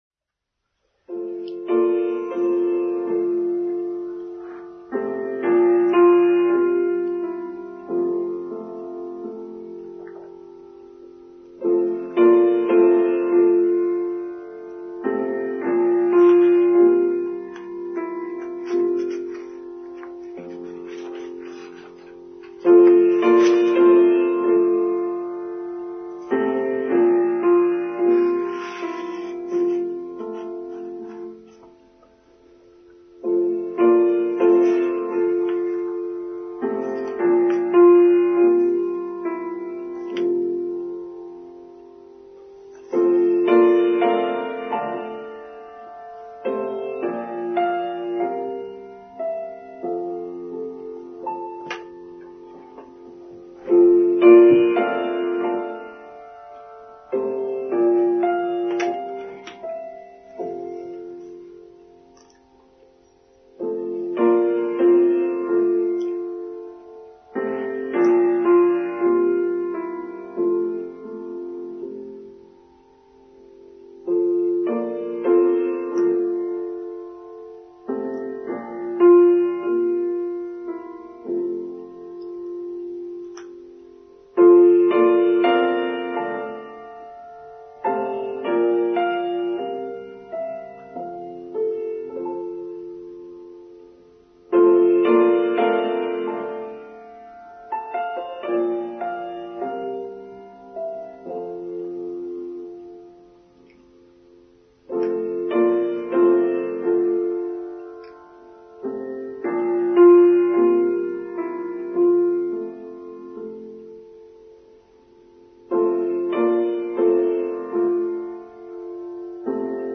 The Way of Taoism: Online Service for 28th August 2022